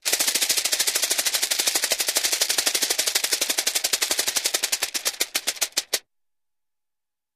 RapidInsectWing CRT042203
Steady, Rapid, Insect-like Wing Flaps; Slows To Stop